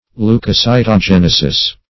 Search Result for " leucocytogenesis" : The Collaborative International Dictionary of English v.0.48: Leucocytogenesis \Leu`co*cy`to*gen"e*sis\ (-s[imac]`t[-o]*j[e^]n"[-e]*s[i^]s), n. [Leucocyte + genesis.]
leucocytogenesis.mp3